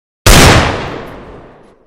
old_deagle.wav